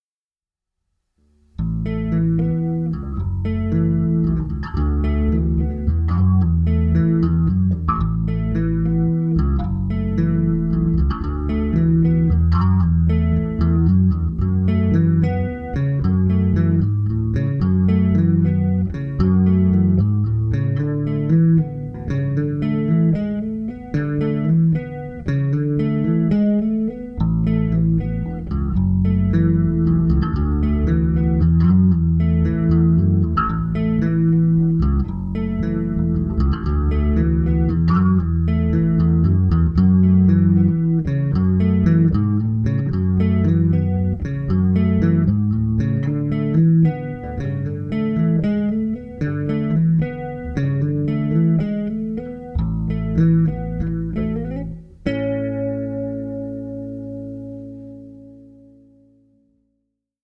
The pickups are powerful and very balanced.
The result is the bass sounds huge and very piano like.